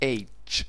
Ääntäminen
Synonyymit horse heroin Ääntäminen : IPA : /ˈeɪtʃ/ UK : IPA : [ˈeɪtʃ] non-standard: IPA : /heɪtʃ/ Haettu sana löytyi näillä lähdekielillä: englanti Käännöksiä ei löytynyt valitulle kohdekielelle.